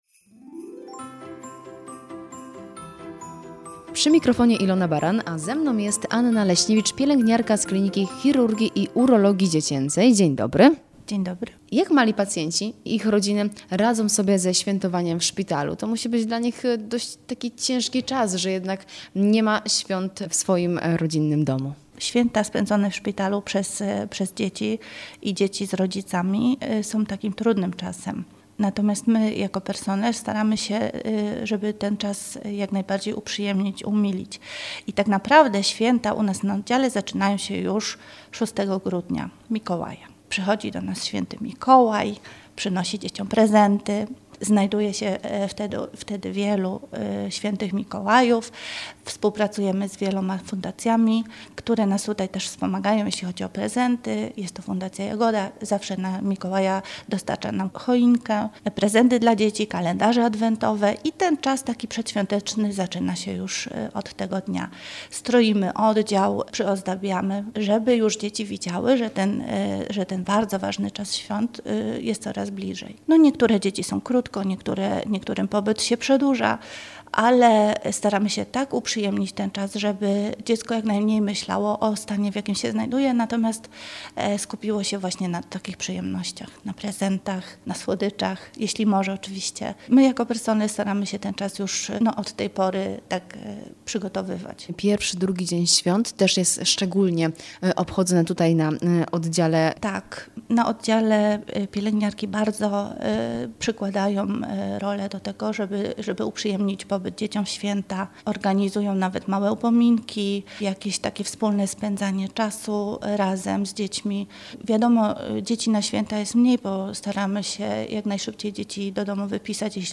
W audycji „Święta na służbie” zaglądamy do Uniwersyteckiego Szpitala Klinicznego we Wrocławiu, aby porozmawiać z lekarzami i pielęgniarkami pracującymi w szpitalu, którzy opowiedzą nam, jak spędzają święta w pracy? Czy czas świąt wpływa na atmosferę?